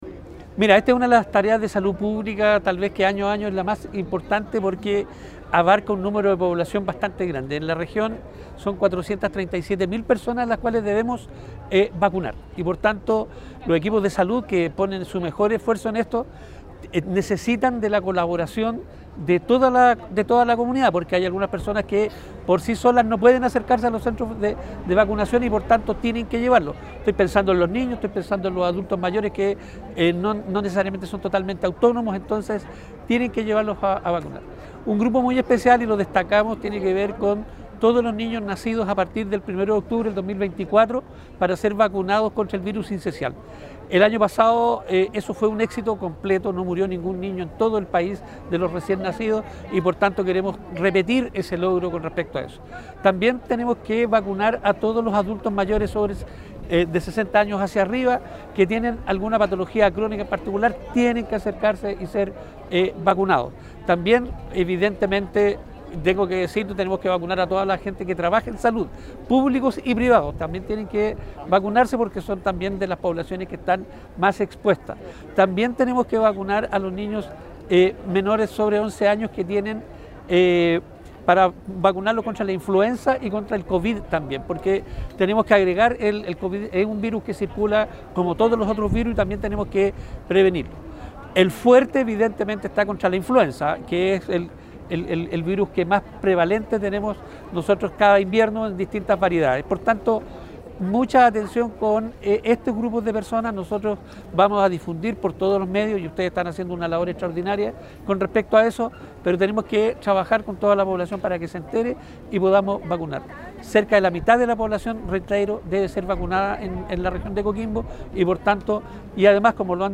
Al respecto, el Director del Servicio de Salud, Ernesto Jorquera explicó
CUNA-DIRECTOR-SERVICIO-DE-SALUD_VACUNACION.mp3